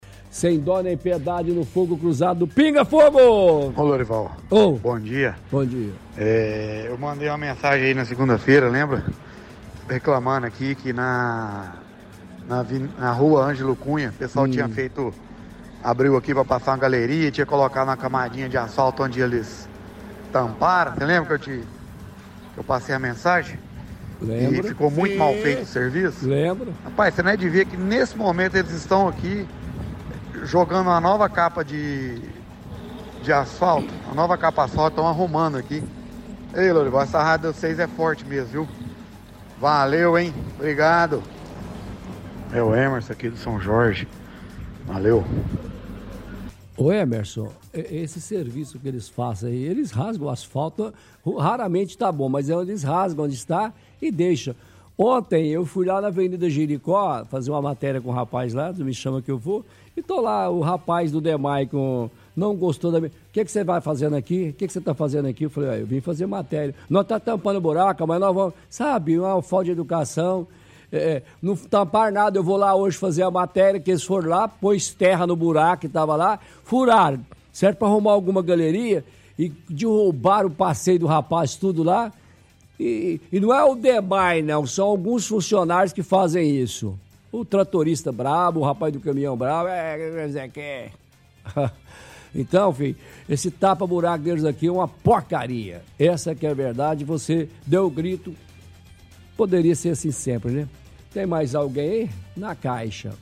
– Ouvinte fala sobre reclamação que havia feito em programa anterior reclamando de recomposição asfáltica após obra do Dmae e afirma que no momento está sendo feito o reparo do local que havia sido mencionado.